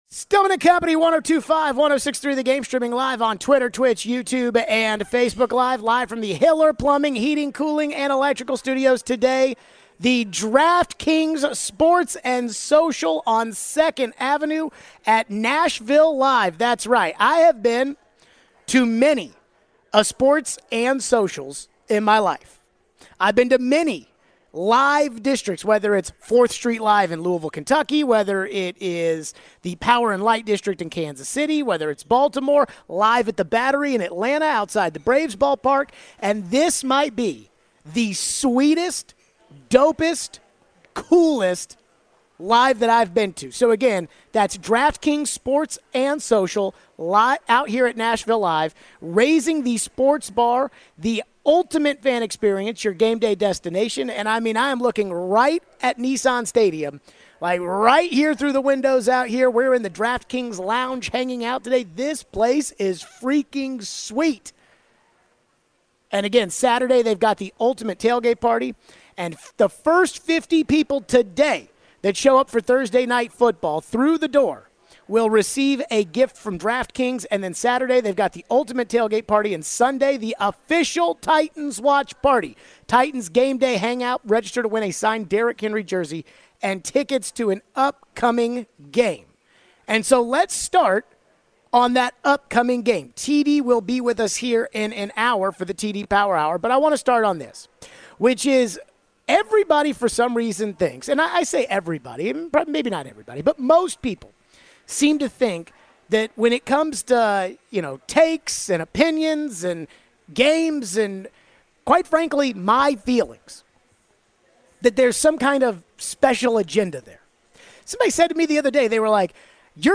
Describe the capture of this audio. What do we think about the possible return of RB Jonathan Taylor this week? We take your phones.